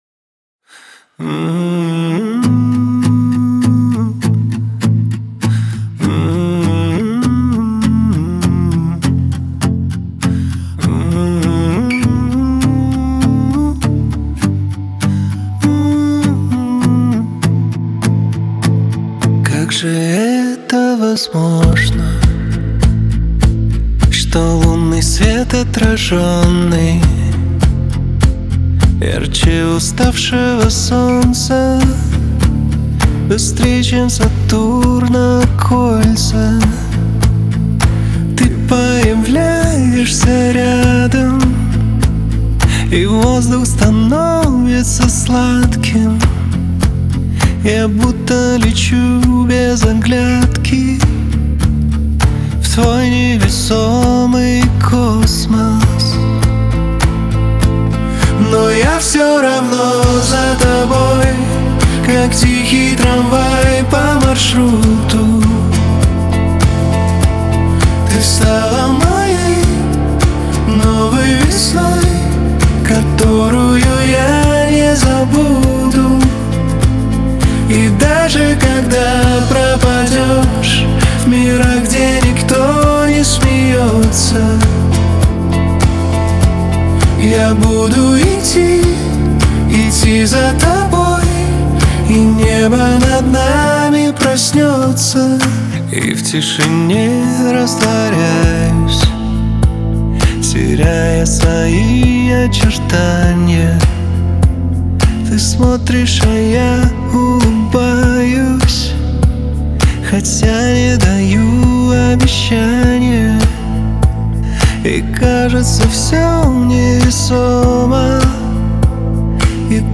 Трек размещён в разделе Рэп и хип-хоп / Русские песни.